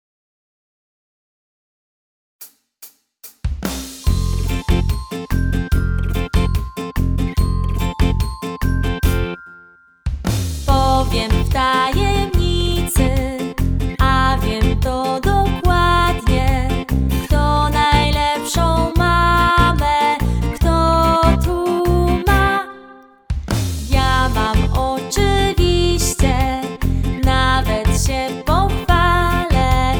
piosenkę w wersji wokalnej i instrumentalnej